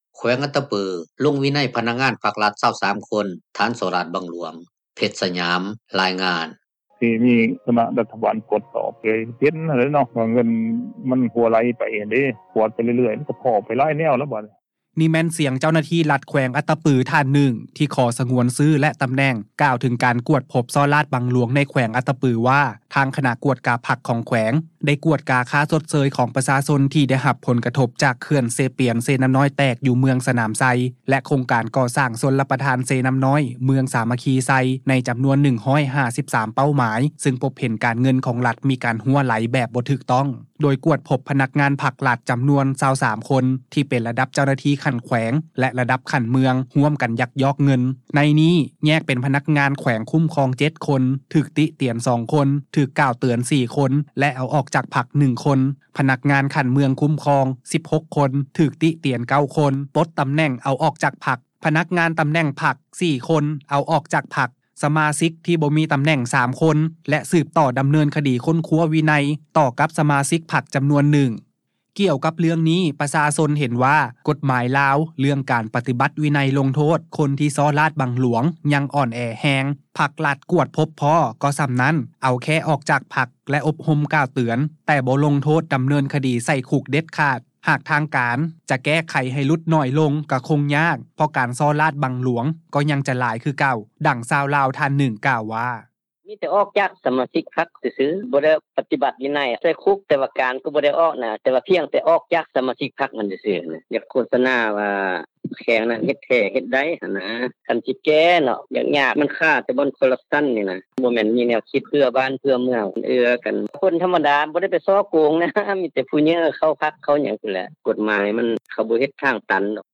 ນີ້ແມ່ນສຽງເຈົ້າໜ້າທີ່ແຂວງອັດຕະປື ທ່ານໜຶ່ງ ທີ່ຂໍສະຫງວນຊື່ ແລະ ຕໍາແໜ່ງ ກ່າວເຖິງການກວດພົບສໍ້ລາດບັງຫຼວງ ໃນແຂວງອັດຕະປື ວ່າ ທາງຄະນະກວດກາ ພັກຂອງແຂວງ ໄດ້ກວດກາ ຄ່າຊົດເຊີຍ ຂອງປະຊາຊົນ ທີ່ໄດ້ຮັບຜົນກະທົບ ຈາກເຂື່ອນເຊປຽນ-ເຊນໍ້ານ້ອຍ ແຕກ ຢູ່ເມືອງສະໜາມໄຊ ແລະ ໂຄງການກໍ່ສ້າງຊົນລະປະທານ ເຊນໍ້ານໍ້ານ້ອຍ ເມືອງສາມັກຄີໄຊ ໃນຈໍານວນ 153 ເປົ້າໝາຍ ເຊິ່ງພົບເຫັນການເງິນຂອງລັດ ມີການຮົ່ວໄຫຼ ແບບບໍ່ຖືກຕ້ອງ ໂດຍກວດພົບພະນັກງານພັກ-ລັດ ຈໍານວນ 23 ຄົນ ທີ່ເປັນລະດັບເຈົ້າໜ້າທີ່ຂັ້ນແຂວງ ແລະ ລະດັບຂັ້ນເມືອງ ຮ່ວມກັນຍັກຍອກເງິນ ໃນນີ້ ແຍກເປັນພະນັກງານແຂວງຄຸ້ມຄອງ 7 ຄົນ ຖືກຕິຕຽນ 2 ຄົນ ຖືກກ່າວເຕືອນ 4 ຄົນ ແລະ ເອົາອອກຈາກພັກ 1 ຄົນ. ພະນັກງານຂັ້ນເມືອງຄຸ້ມຄອງ